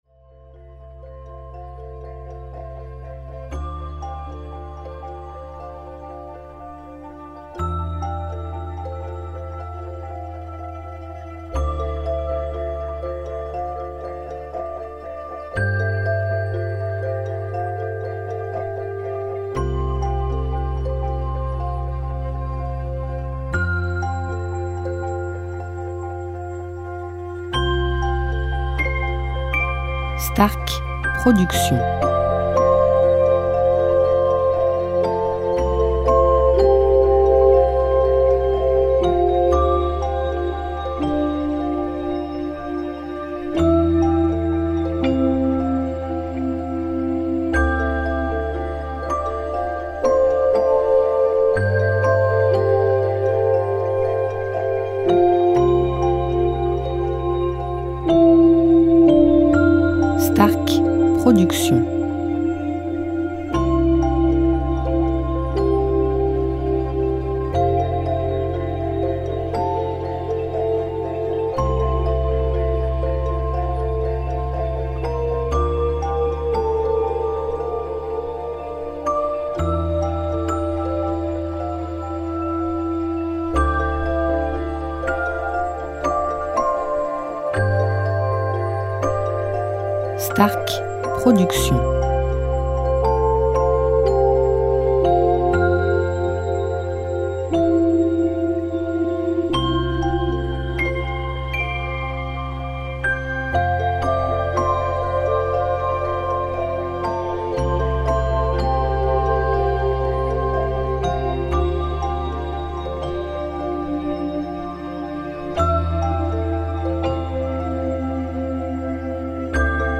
style Relaxation Chillout Lounge Ambient Electro
style NewAge Worldmsuic